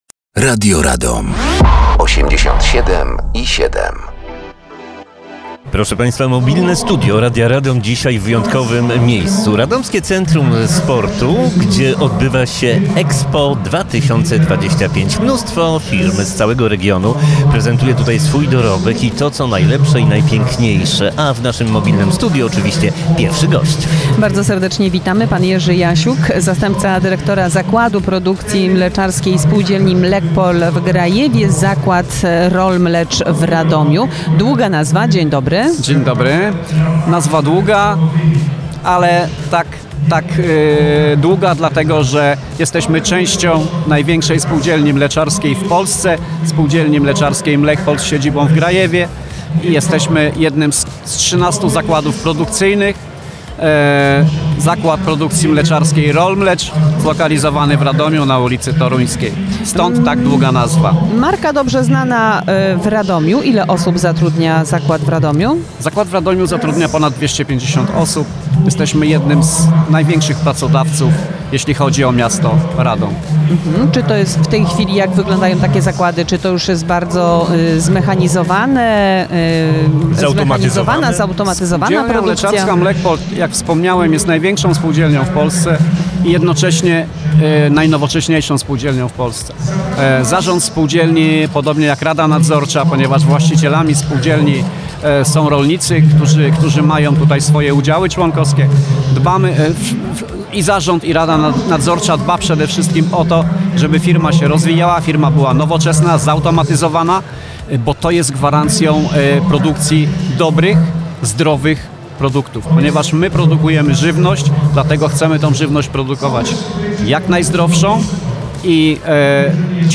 Mobilne Studio